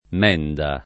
menda [ m $ nda ]